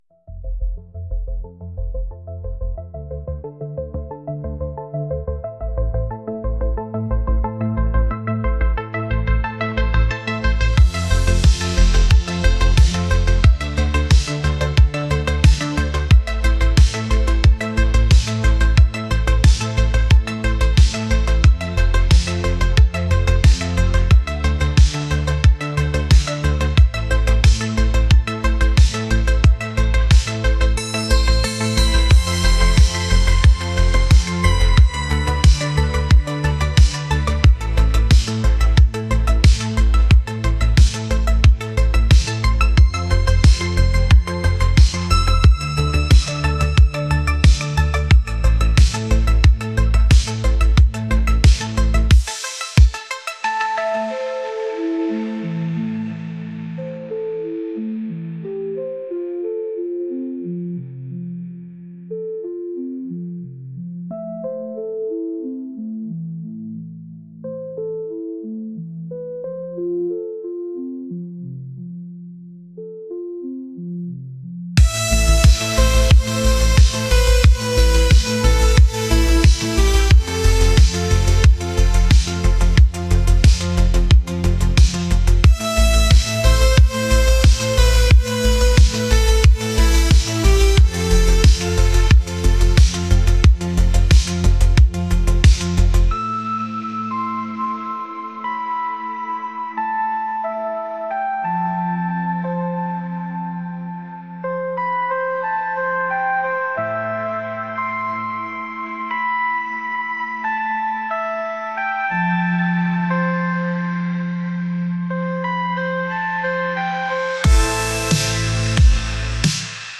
dreamy | electronic